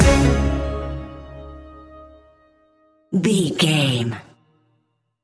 Ionian/Major
C♯
electronic
techno
trance
synths
synthwave
instrumentals